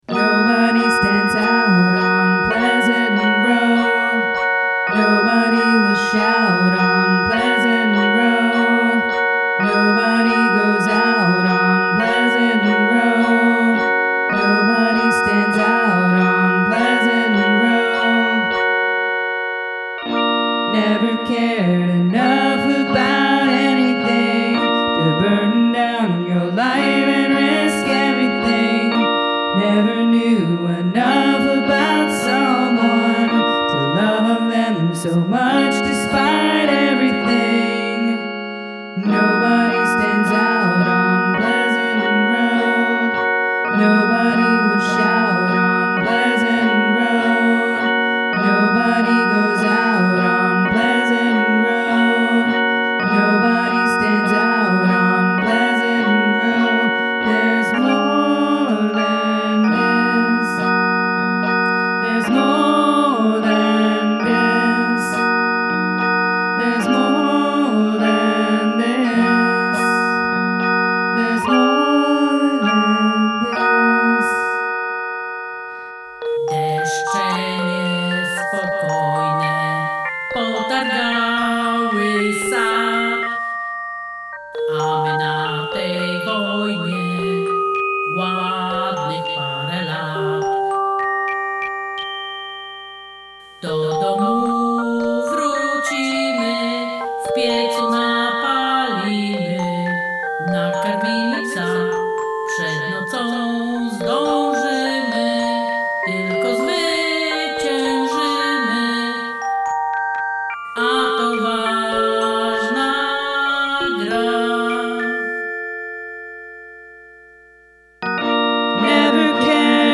Must include a guest singing or speaking in another language
My interest continues to be piqued by the omnichord.